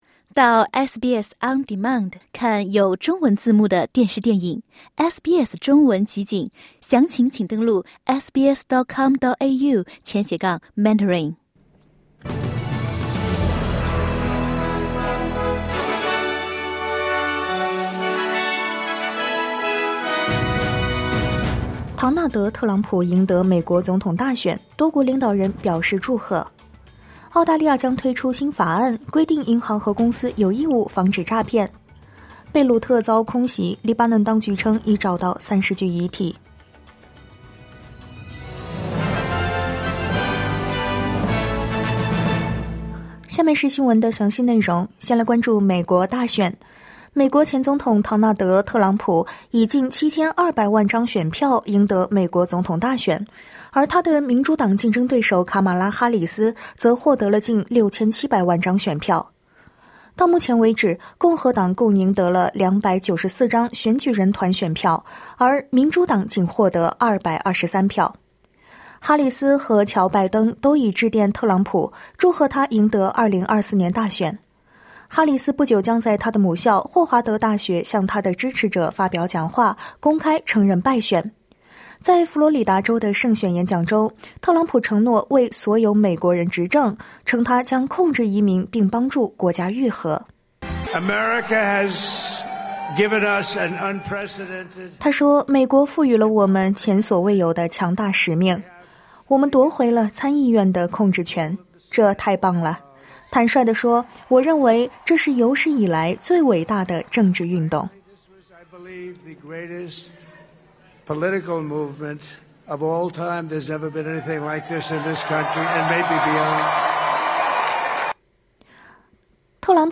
SBS早新闻（2024年11月7日）